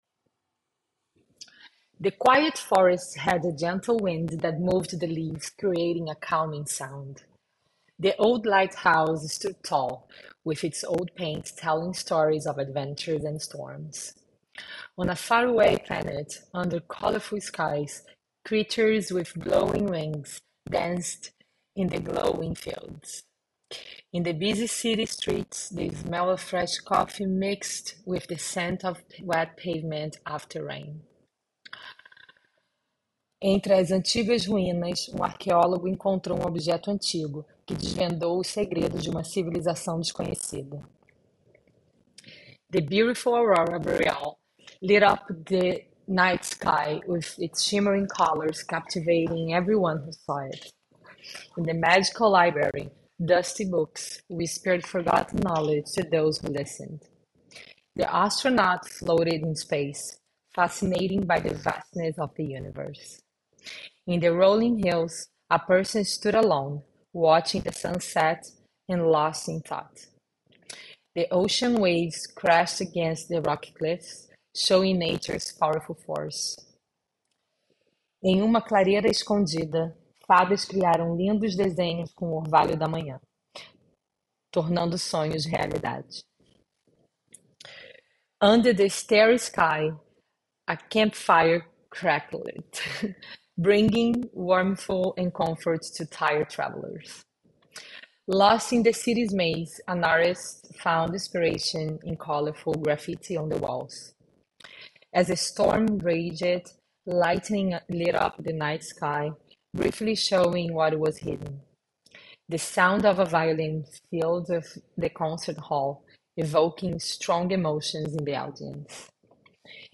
Portuguese-withNoise.MP3